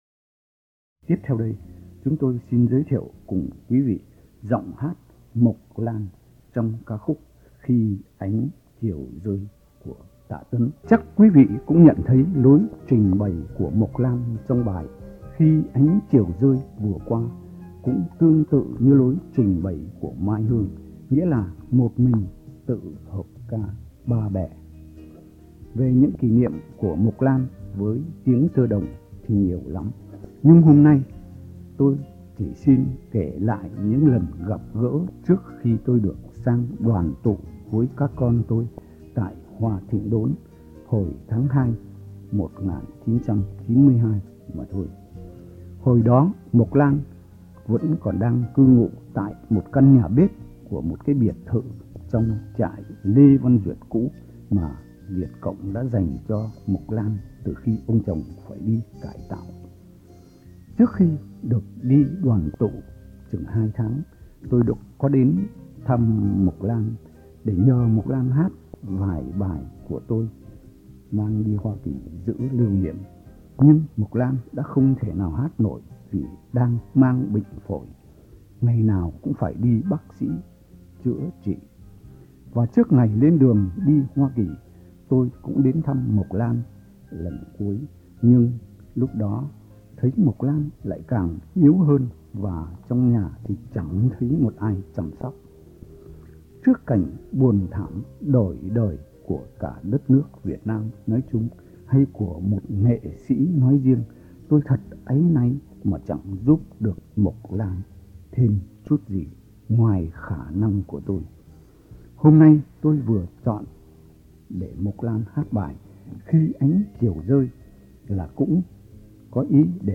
Hoàng Trọng giới thiệu Mộc Lan